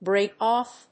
アクセントbrèak óff